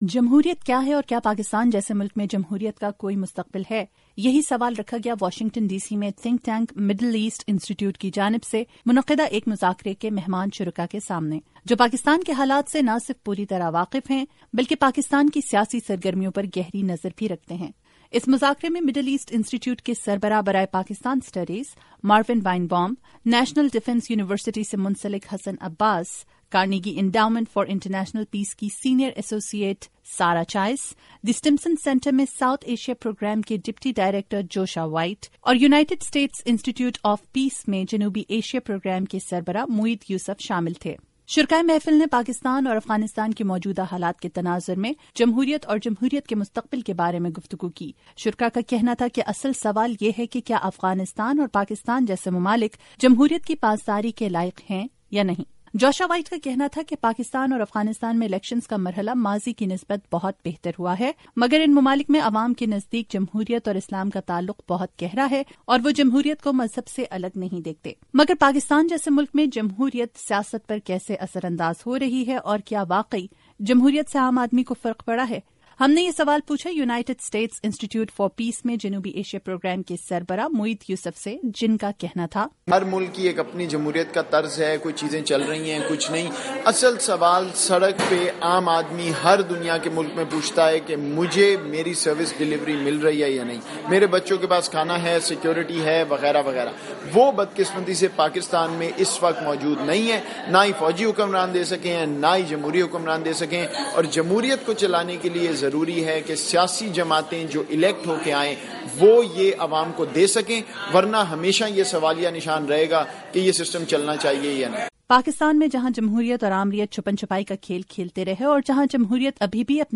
پاکستان میں جہاں جمہوریت اور آمریت چھپن چھپائی کا کھیل کھیلتے رہے اور جہاں جمہوریت ابھی بھی اپنے پیر جمانے کی تگ و دو کر رہی ہے، یہ کوشش کس حد تک کامیاب رہے گی؟ اسی سوال کا جواب تلاش کرنے کی کوشش کی کئی واشنگٹن میں منعقدہ ایک تقریب میں۔ تفصیلات آڈیو رپورٹ میں۔